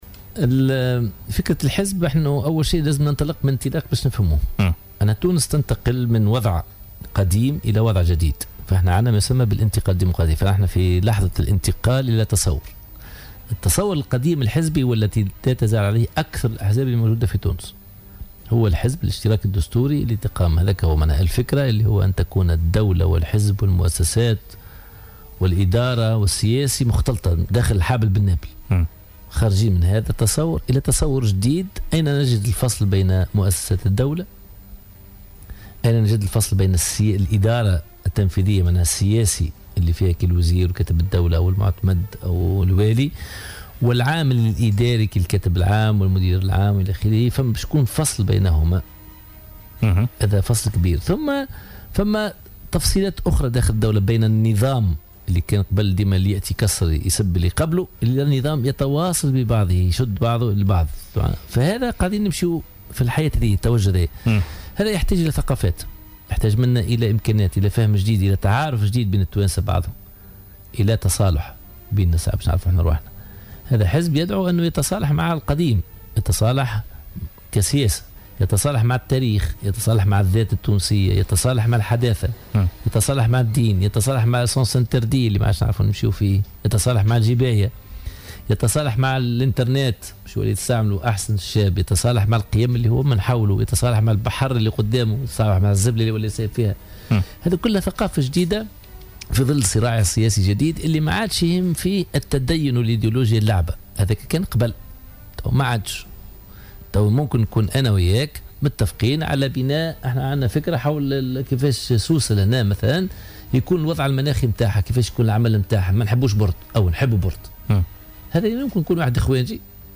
قال رئيس حزب المصالحة والمرشح للانتخابات الرئاسية القادمة منار الإسكندراني اليوم الجمعة في مداخلة له في برنامج "بوليتيكا" إن حركة النهضة لا تزال جمعية تضم توجهات سياسية كثيرة.